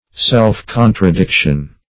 self-contradiction - definition of self-contradiction - synonyms, pronunciation, spelling from Free Dictionary
Self-contradiction \Self`-con`tra*dic"tion\, n.